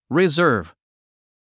発音
rizə’ːrv　リィザーブ